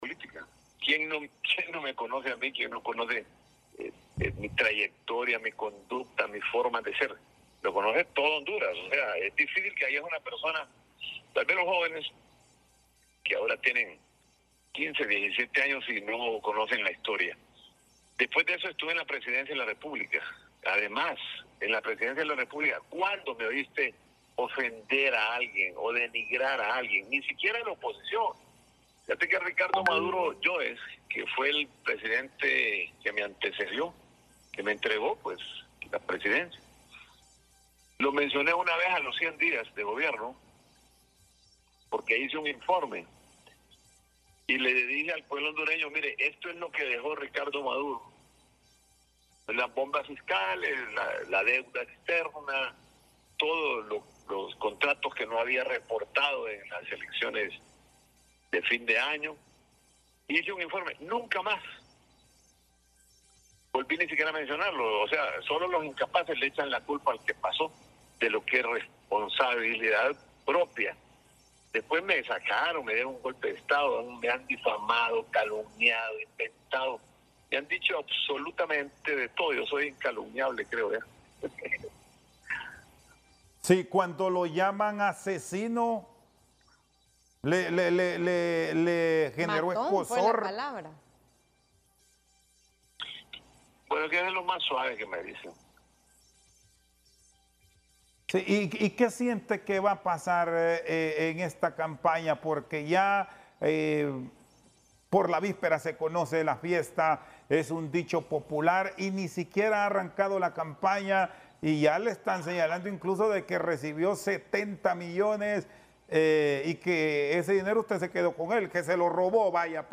El expresidente de Honduras, Manuel Zelaya Rosales, reaccionó en el programa La Tarde de HRN y TSi, sobre la acusación que hizo el candidato a la presidencia, Salvador Nasralla, que lo vendió por 70 millones de lempiras en las elecciones pasadas.